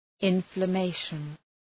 {,ınflə’meıʃən}